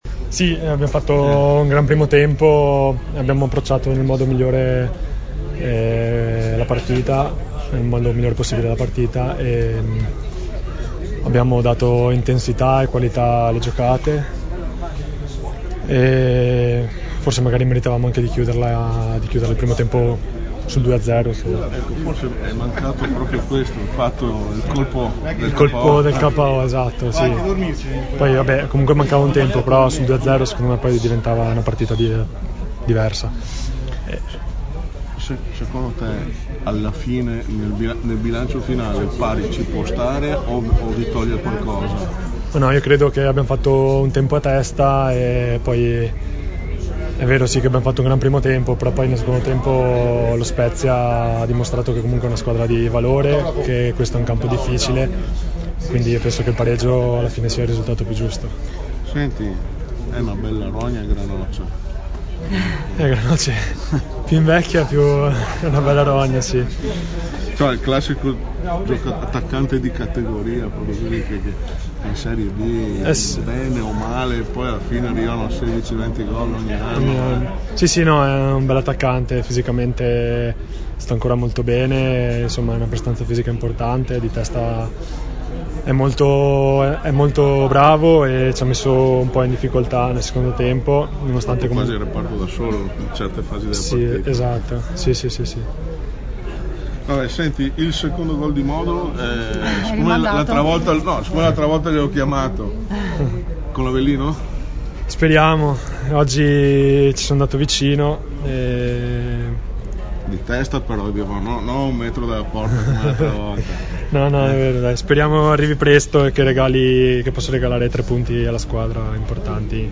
nella sala stampa dello stadio Picco